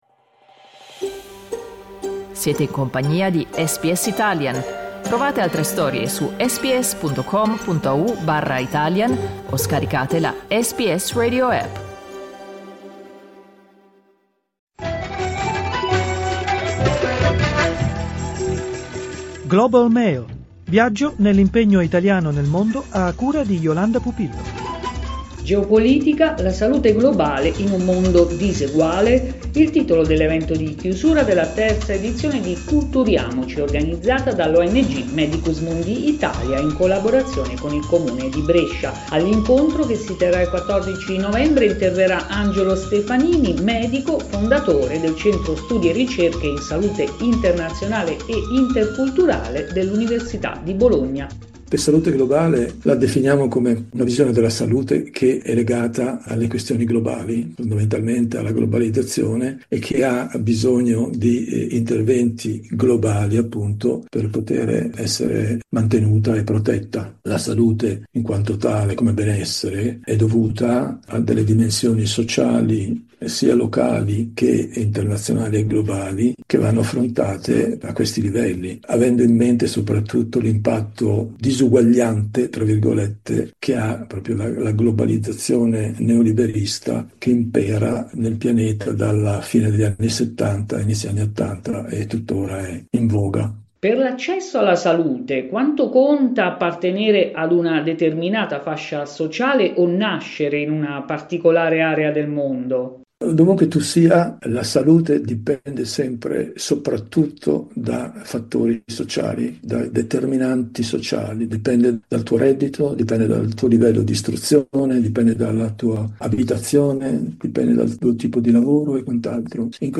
Clicca sul tasto "play" in alto per ascoltare l'intervista integrale